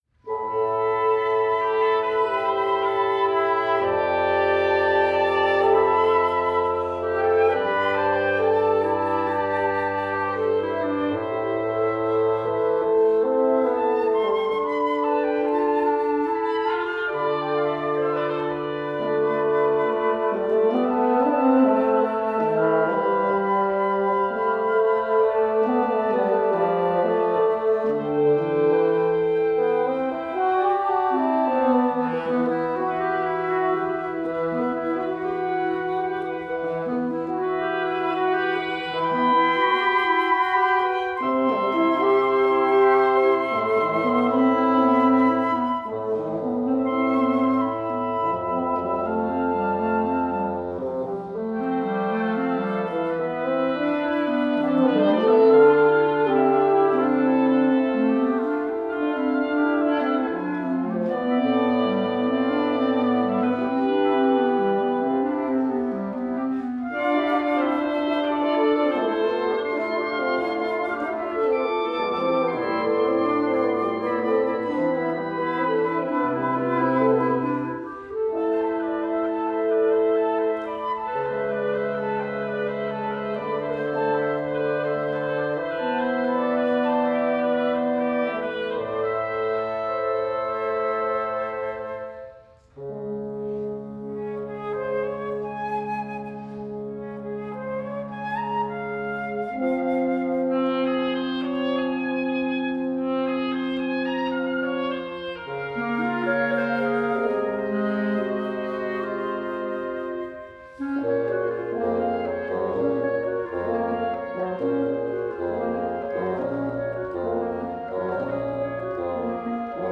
Woodwind Quintet (Flute, Oboe, Clarinet, Horn, Bassoon)